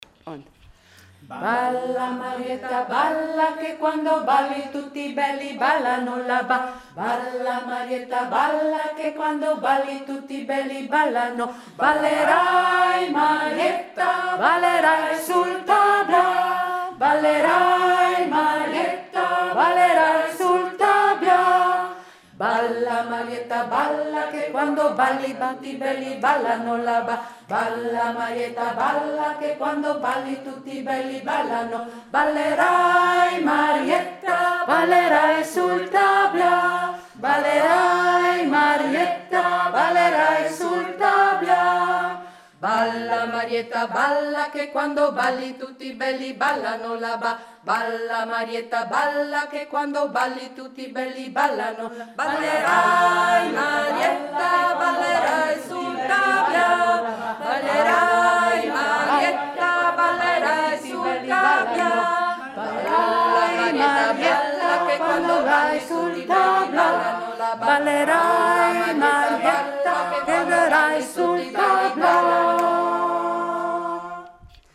cantato da noi